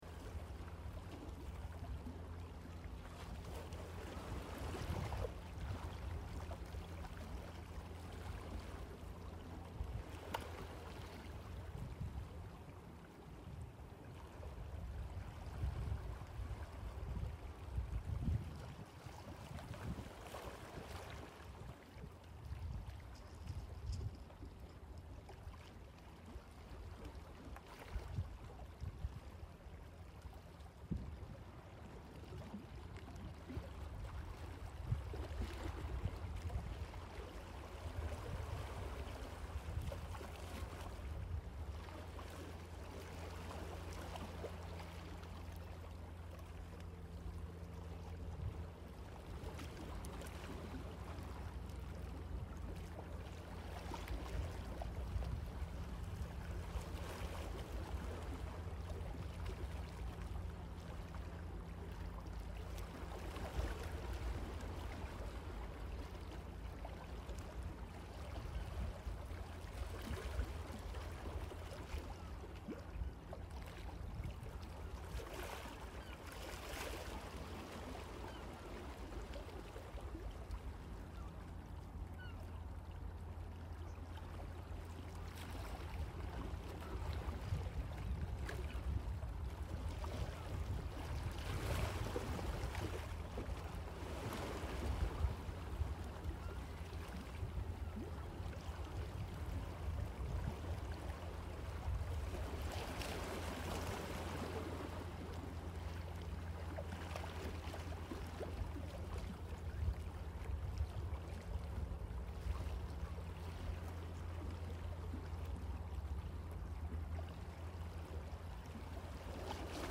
Access the benefits of “Virtual Sea” by playing the sounds of the ocean as you go about your day – or to set the tone for your meditation or yoga practice.
Listen or download the audio recording of the sounds of the ocean below, and use this to relax and calm you whenever you feel stressed or overwhelmed.
Sounds-of-the-Sea-from-Sea-Soul-Blessings-1.mp3